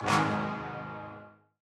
TM-88 Hits [Goonies]_3.wav